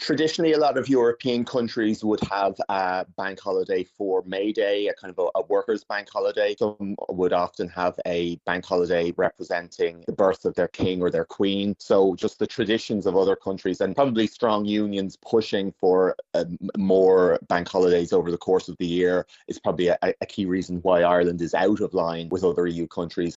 The TD explains why Ireland only has 10 bank holidays compared to the European average of 12: